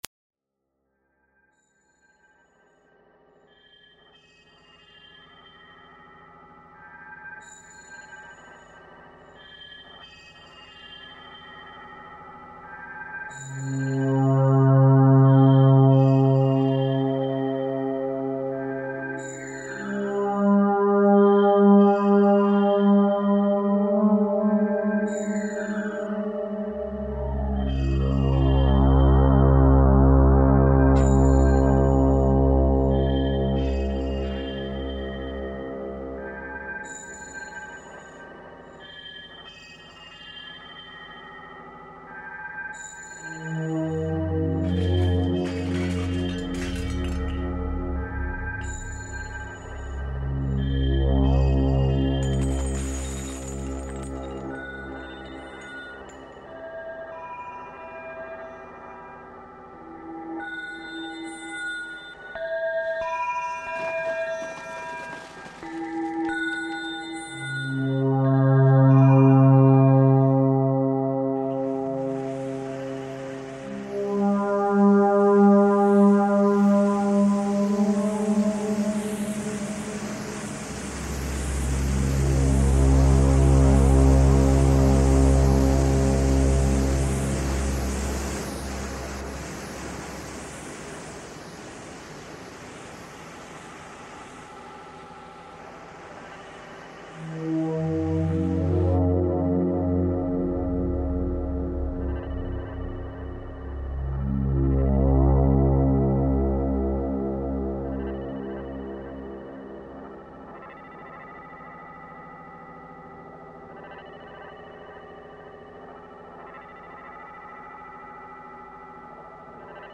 File under: Avantgarde